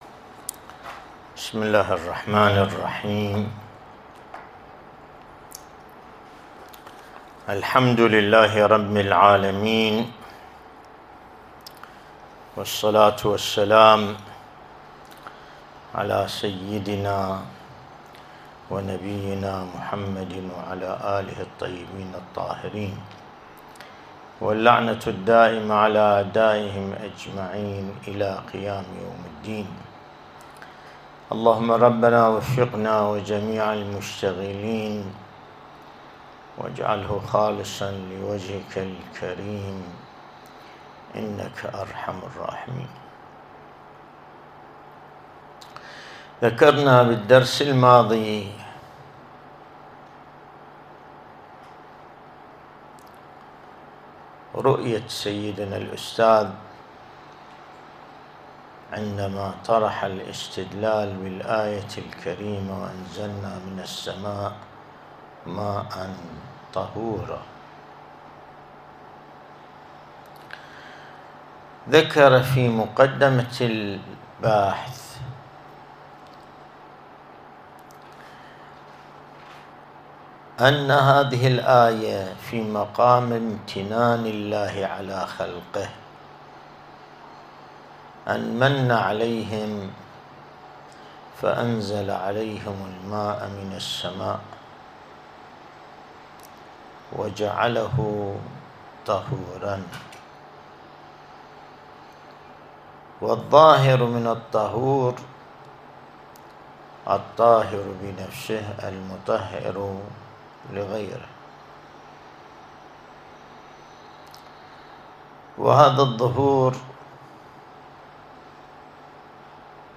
الدرس الاستدلالي شرح بحث الطهارة من كتاب العروة الوثقى لسماحة آية الله السيد ياسين الموسوي(دام ظله)